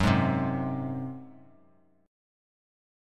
F#sus2b5 Chord